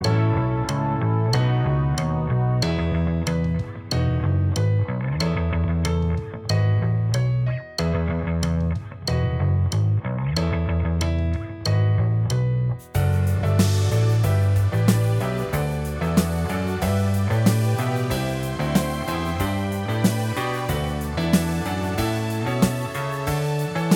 Minus All Guitars Pop (1970s) 4:19 Buy £1.50